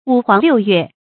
五黃六月 注音： ㄨˇ ㄏㄨㄤˊ ㄌㄧㄨˋ ㄩㄝˋ 讀音讀法： 意思解釋： 指陰歷五、六月間天氣最炎熱的時候。